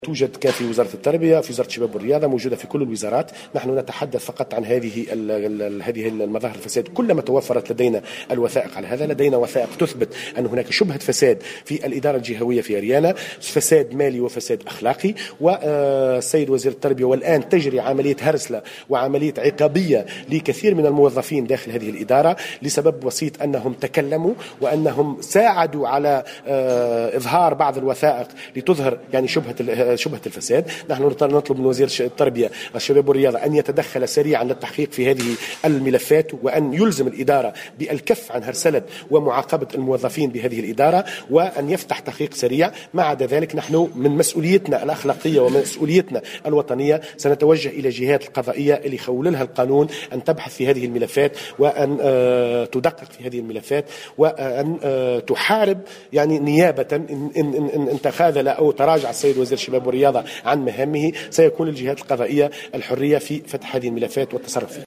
خلال ندوة صحفية عقدتها النقابة اليوم الجمعة